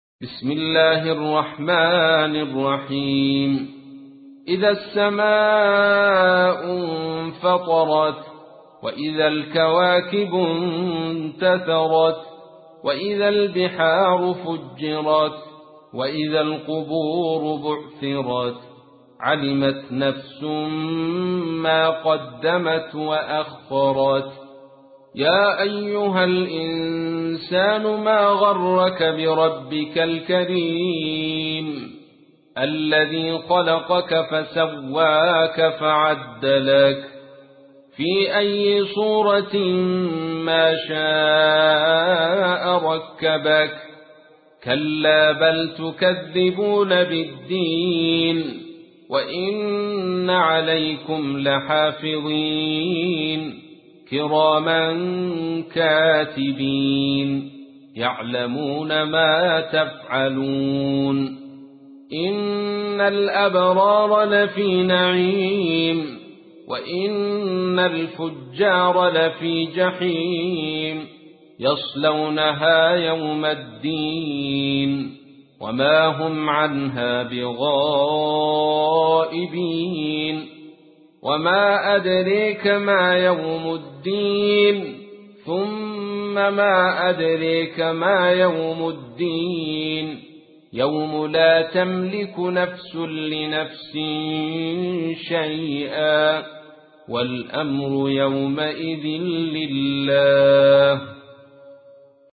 تحميل : 82. سورة الانفطار / القارئ عبد الرشيد صوفي / القرآن الكريم / موقع يا حسين